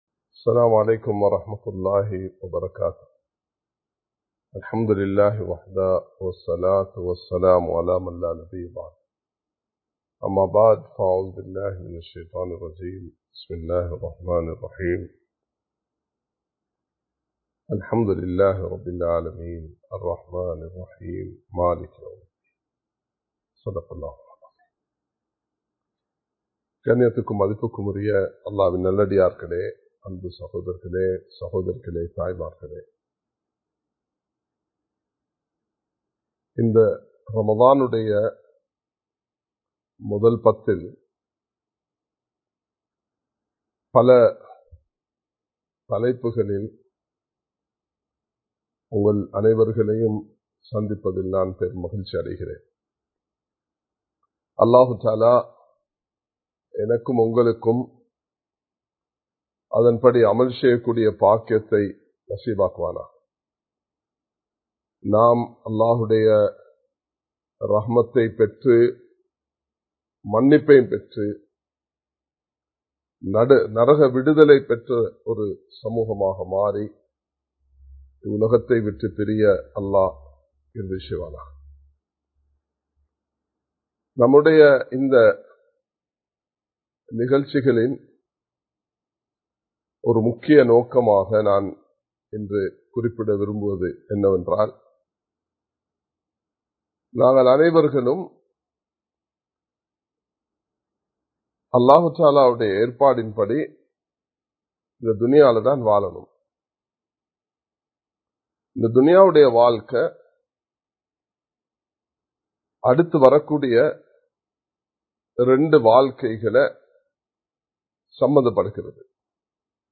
அல்லாஹ்வுடைய இரக்கம் | Audio Bayans | All Ceylon Muslim Youth Community | Addalaichenai
Live Stream